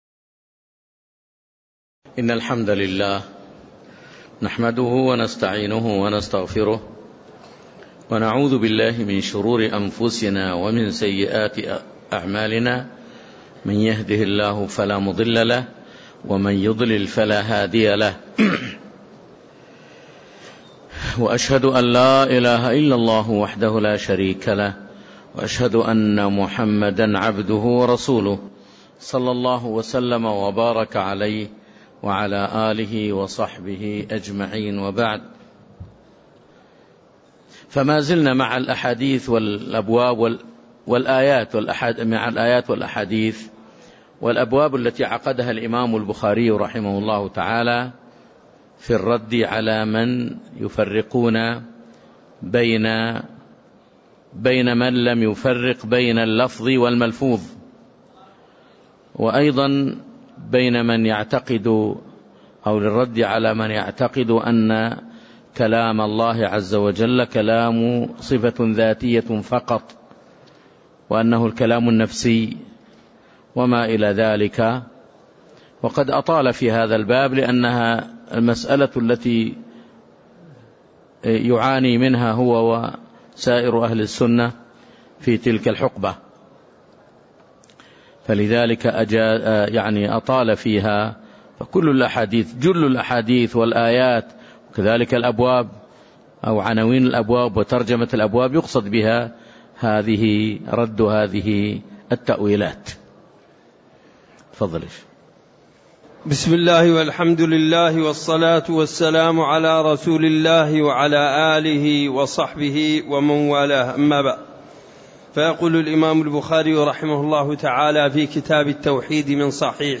تاريخ النشر ٨ صفر ١٤٣٦ هـ المكان: المسجد النبوي الشيخ